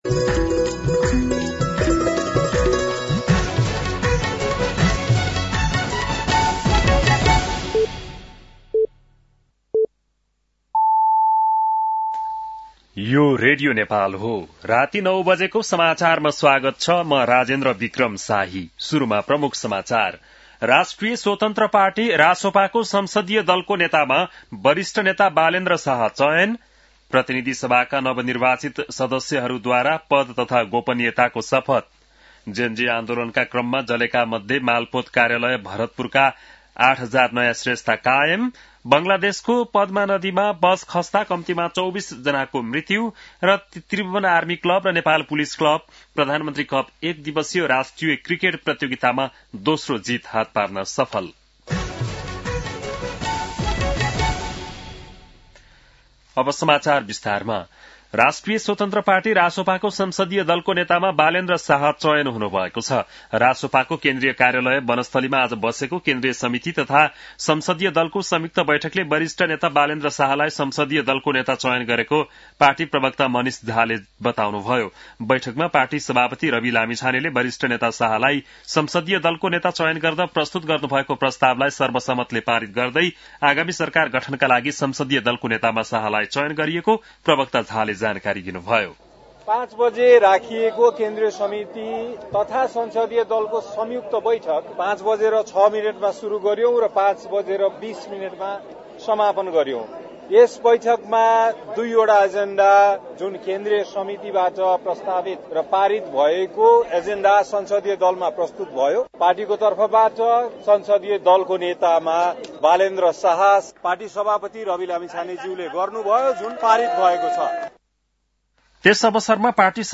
बेलुकी ९ बजेको नेपाली समाचार : १२ चैत , २०८२